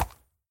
sounds / mob / horse / soft4.mp3